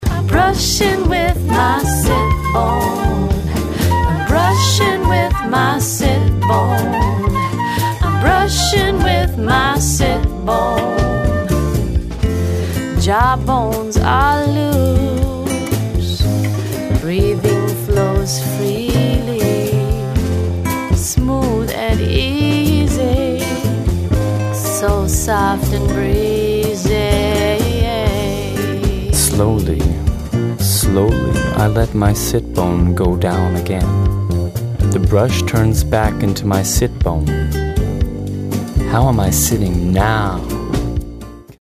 • Speaker guides through exercises
Recorded at: Dschungelstudios Vienna, Austria